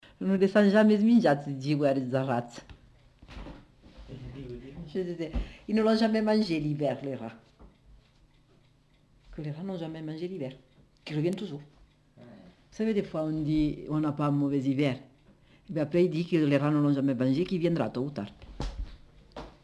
Aire culturelle : Comminges
Lieu : Cathervielle
Type de voix : voix de femme
Production du son : récité
Classification : proverbe-dicton